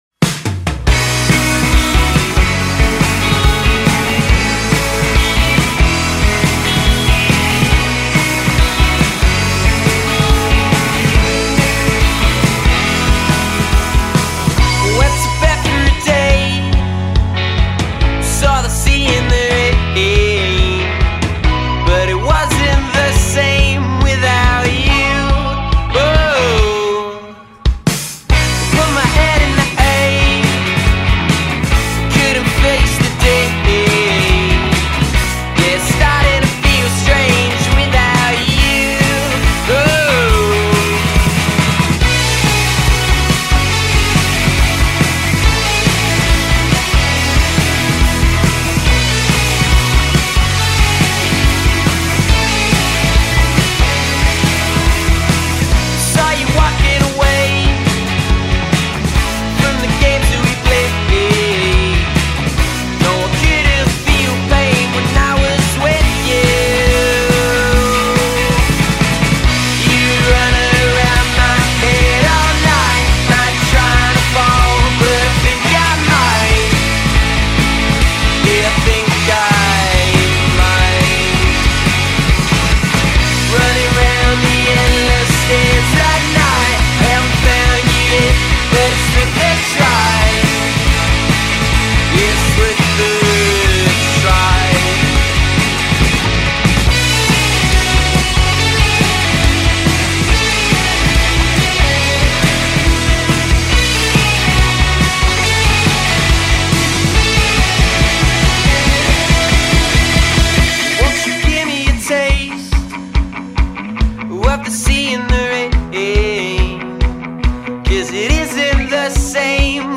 indie pop-rock